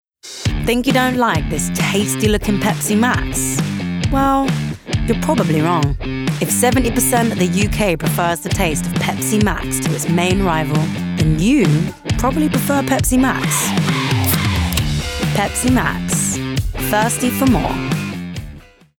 London
Conversational Assured